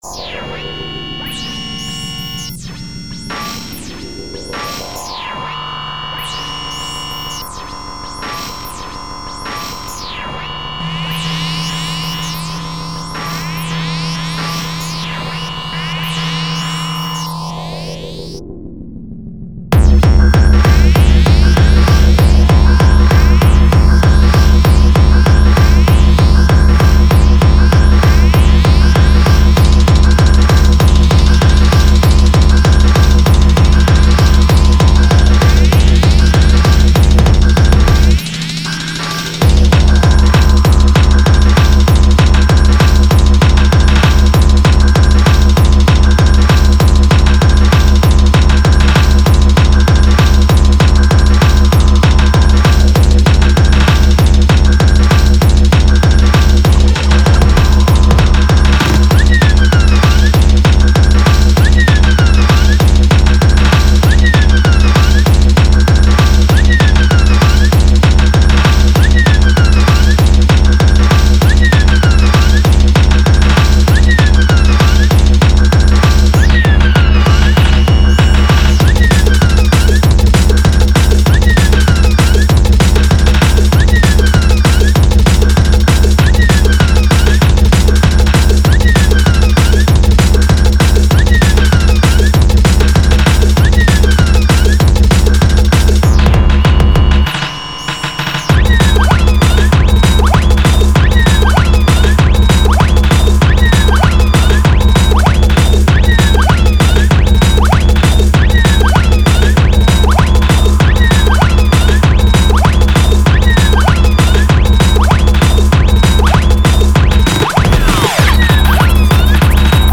Hard TeKnO